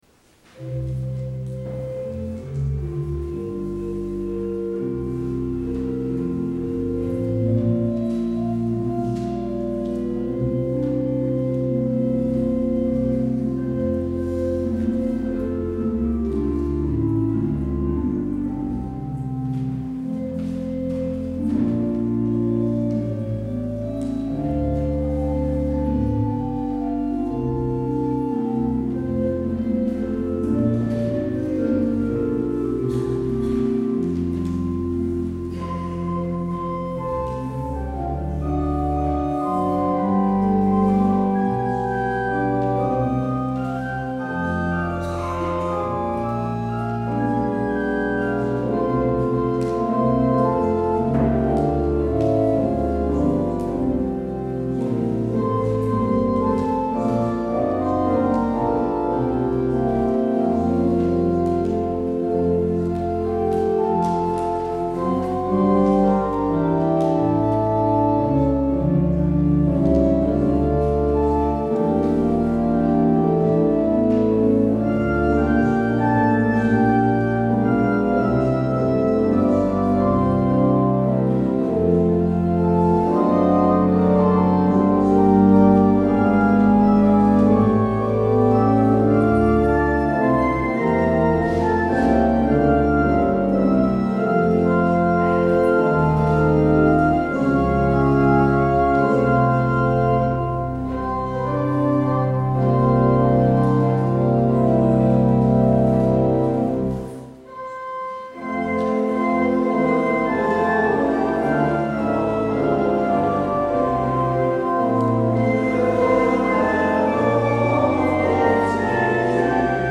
Daarover gaat het, aan de hand van Psalm 119, in deze middagpauzedienst. Als openingslied hoort u Psalm 119:1,8 (NPB). Het slotlied is Ga met God en Hij zal met je zijn.